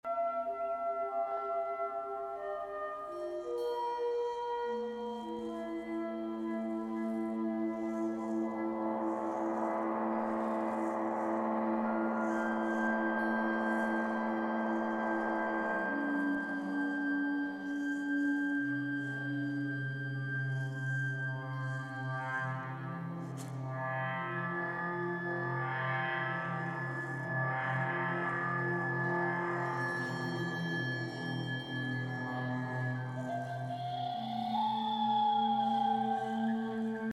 Orgue d'acier
Tout le monde connaît le principe du verre que l’on fait « chanter » en faisant tourner un doigt humide sur son bord. L’orgue d’acier utilise le même principe.
La transformation de la vibration, provoquée par le frottement des doigts sur le tube de friction, en un ensemble cohérent de fréquences est réalisée par trois types différents de mécaniques gérant les fréquences basses, médium et aiguës sur quatre octaves chromatiques.
SON-ORGUE-DACIER.mp3